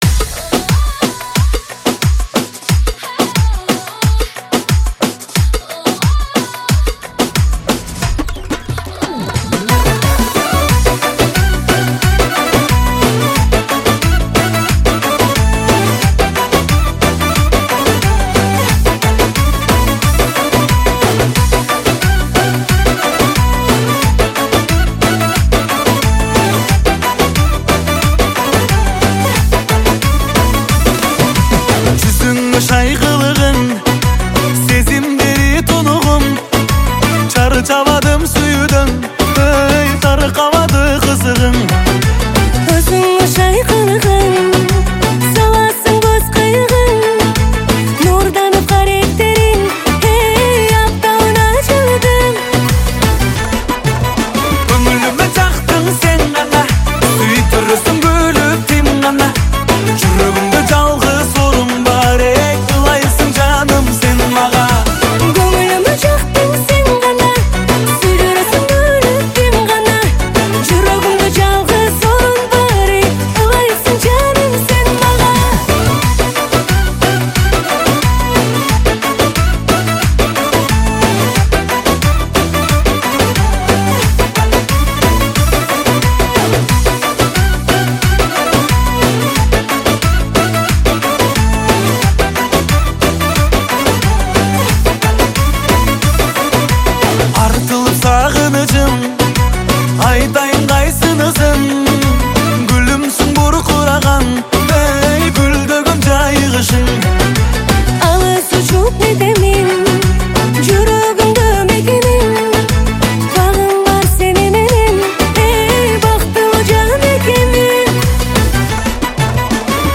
• Категория: Кыргызские песни